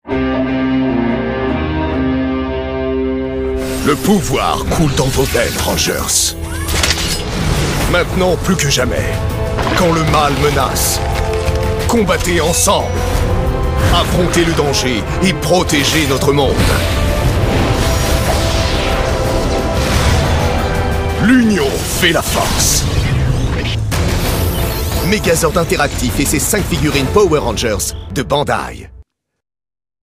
Voix-off dynamique et adaptative pour la publicité Power Rangers Megazord
Spot tv des figurines Power Rangers Megazord interactif.
Ma voix grave et dynamique a parfaitement convenu pour ce produit à thématique guerrière. J’ai su donner le ton d’action et de défi nécessaire pour faire ressortir l’esprit de ce jouet.
Ainsi, même si ma voix évoquait un univers de guerre et de défi, le ton restait adapté aux enfants.